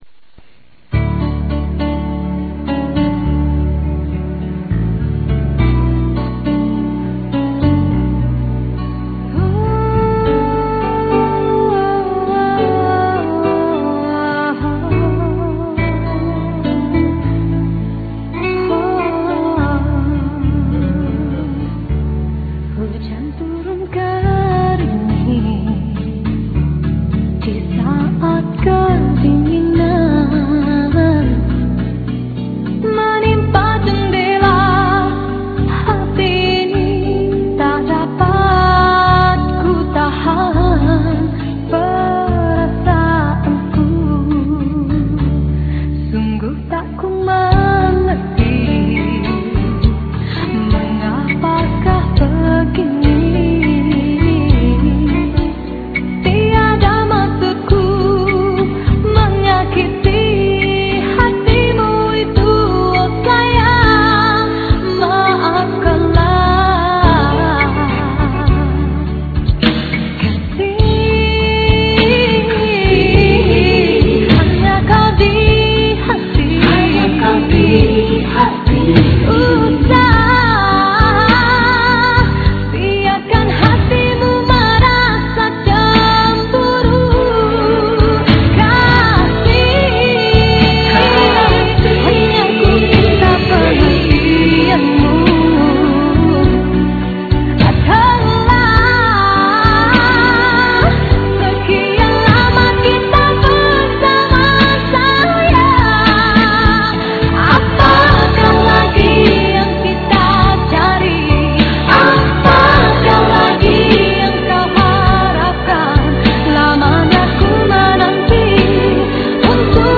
395kb(mono)